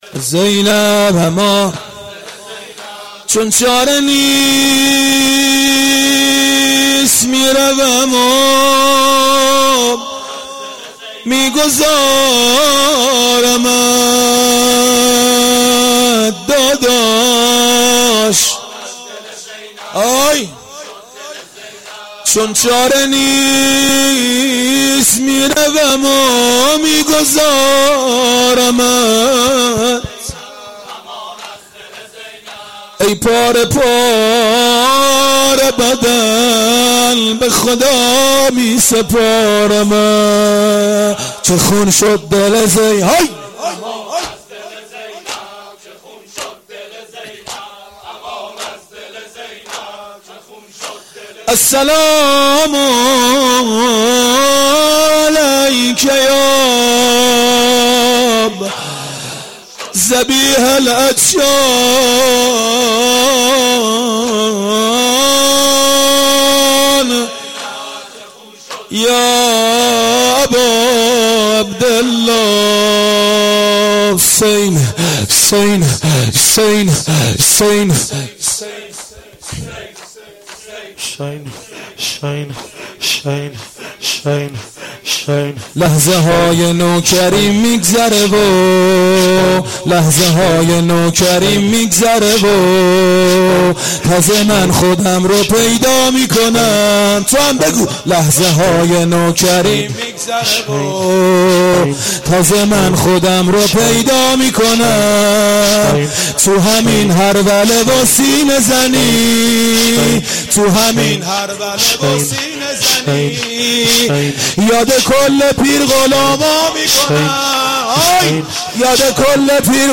مداحی
محرم سال1397